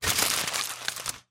Звуки фольги
Сминаем легко